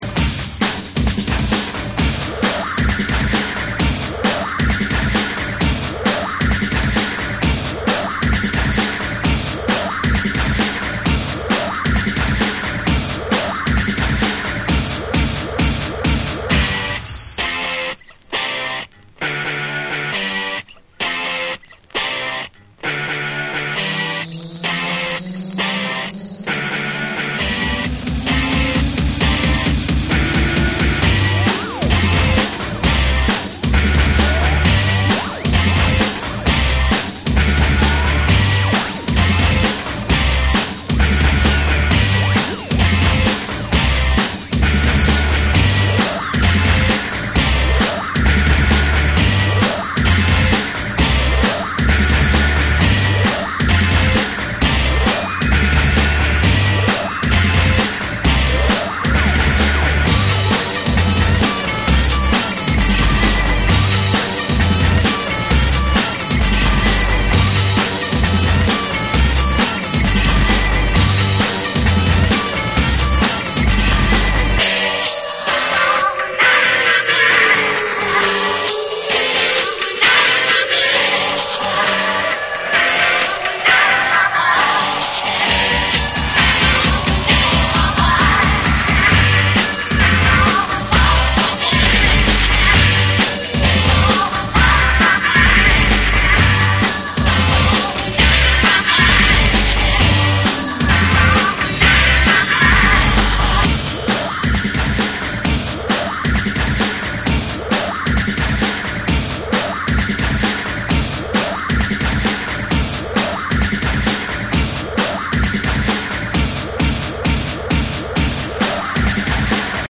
よりファンキーでサイケな質感に再構築された